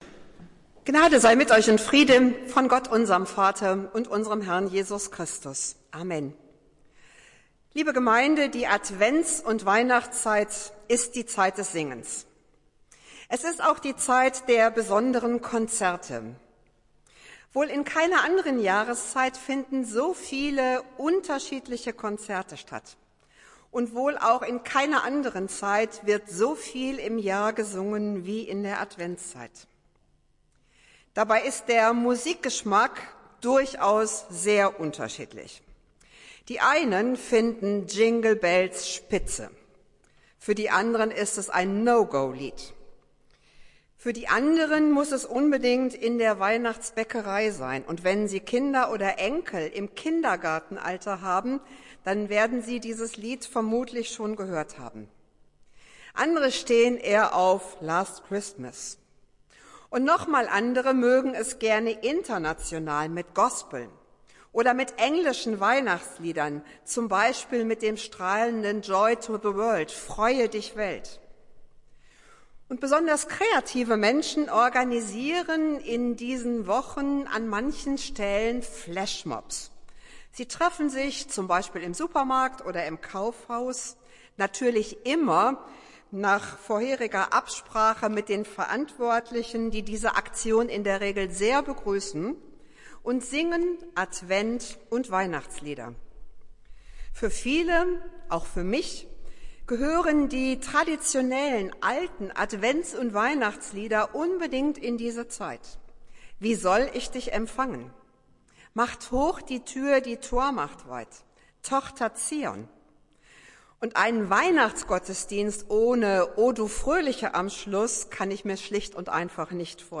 Predigt des Gottesdienstes aus der Zionskirche vom Sonntag, 12.12.2021
Wir haben uns daher in Absprache mit der Zionskirche entschlossen, die Predigten zum Nachhören anzubieten.